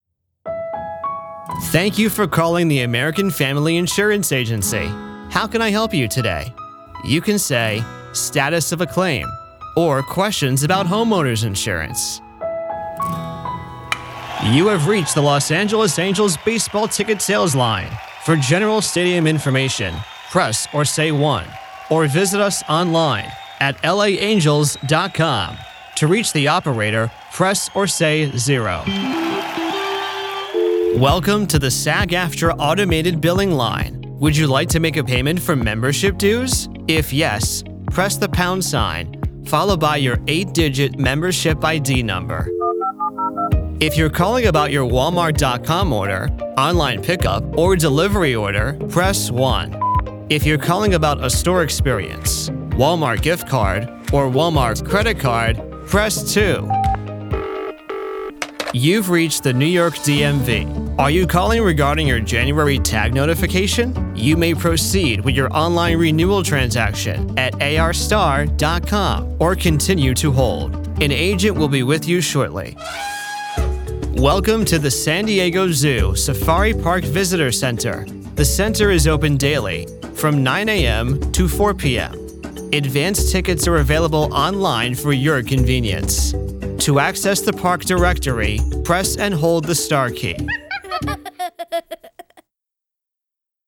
IVR Demo
English-North American, English-Neutral
Young Adult